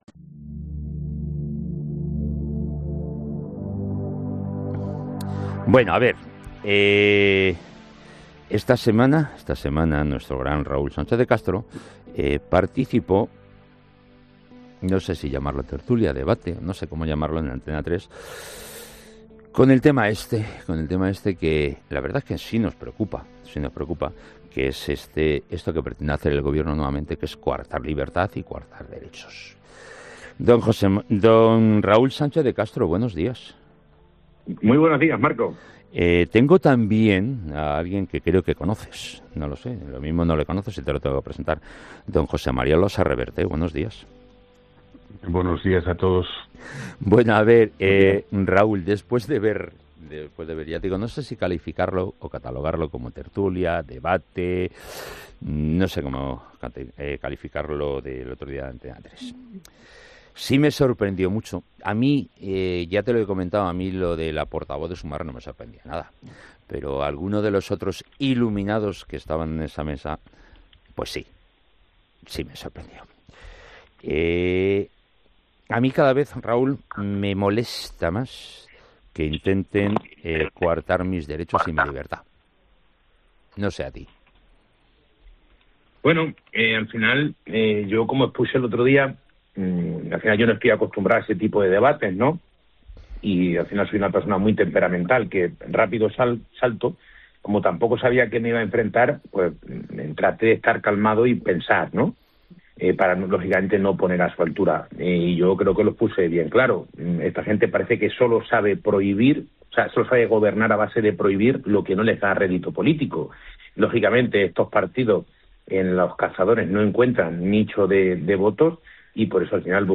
Defensa de la patria potestad: Los tertulianos argumentan firmemente que los padres son quienes tienen el derecho y la responsabilidad de educar a sus hijos según sus propios valores, incluyendo la enseñanza de la caza.